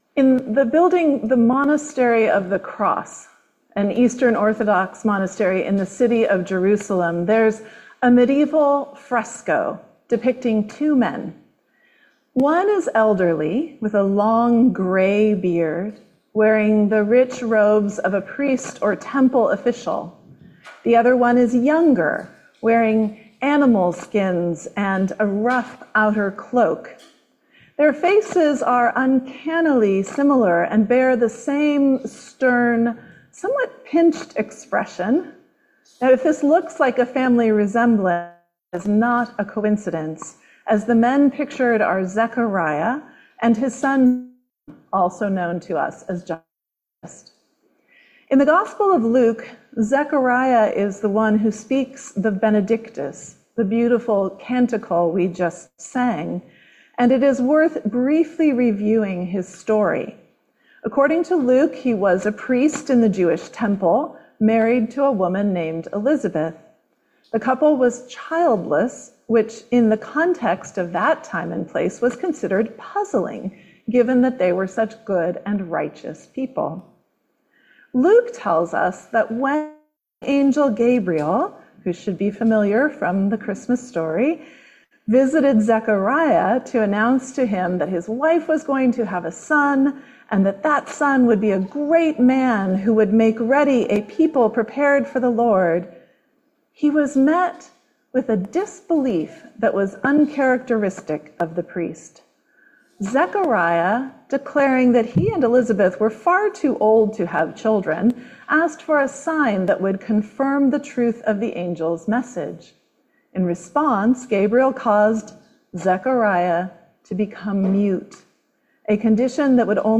Sermon by